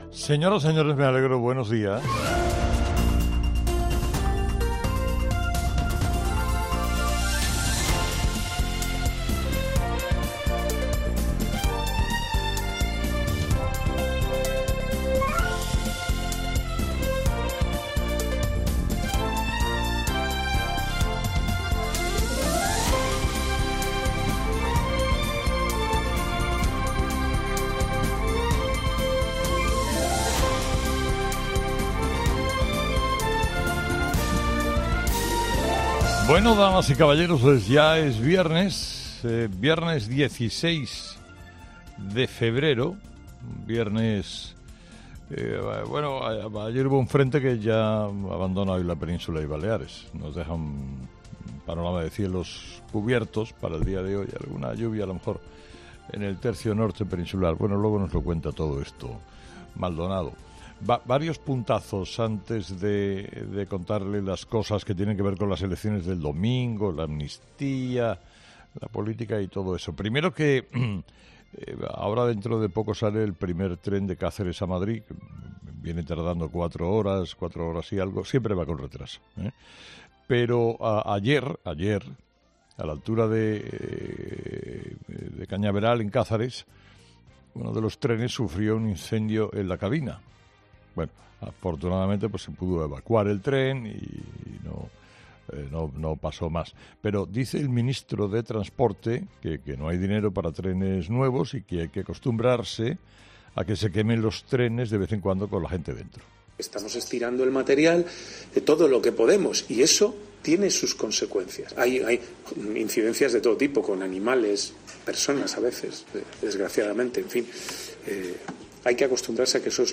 Escucha el análisis de Carlos Herrera a las 06:00 en Herrera en COPE del viernes 16 de febrero
Carlos Herrera, director y presentador de 'Herrera en COPE', comienza el programa de este viernes analizando las principales claves de la jornada que pasan, entre otras cosas, por lo que va a pasar en las elecciones de Galicia de este domingo.